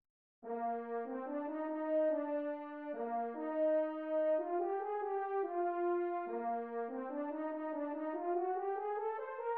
The first horn and top strings introduce another secondary figure similar to the secondary motif during "sunrise", a secondary rhythm to be featured at the summit.